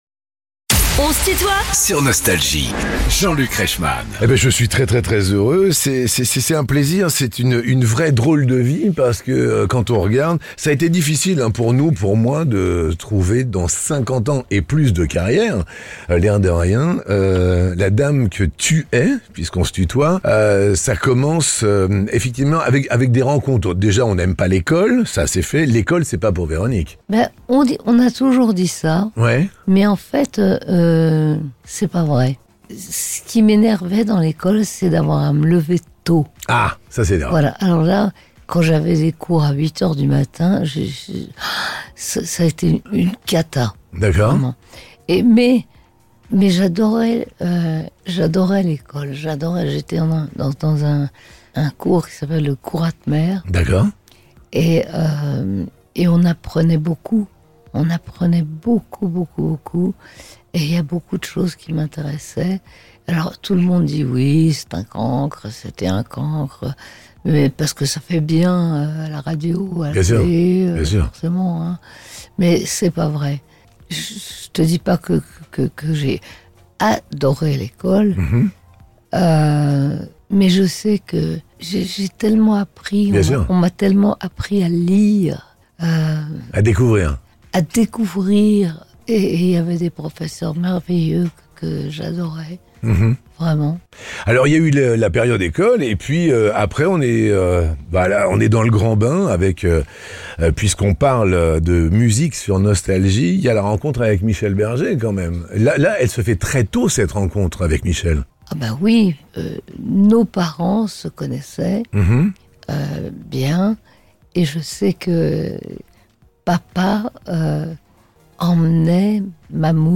Véronique Sanson est l'invitée de "On se tutoie ?..." avec Jean-Luc Reichmann